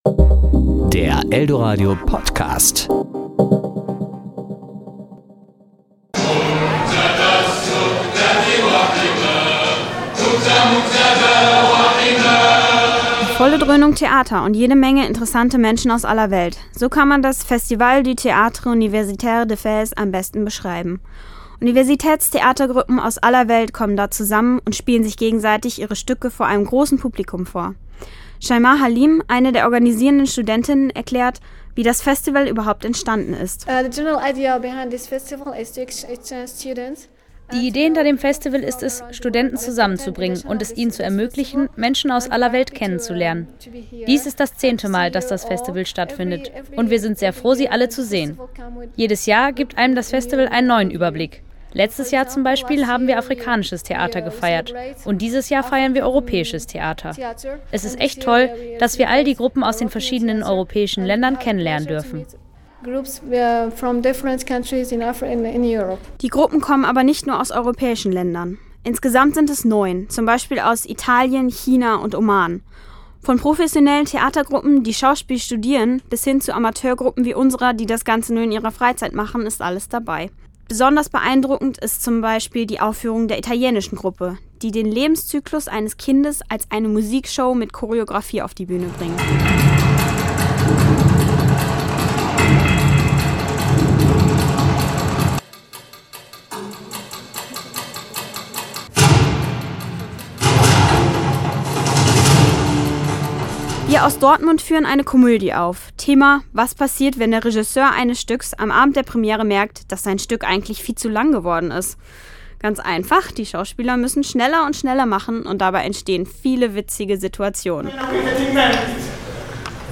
Beiträge  Ressort: Wort  Sendung